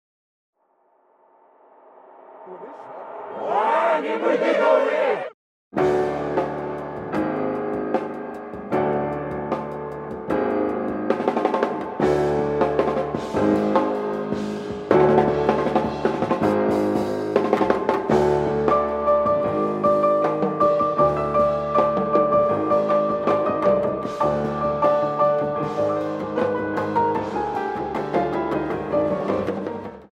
piano trio